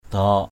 /d̪ɔ:ʔ/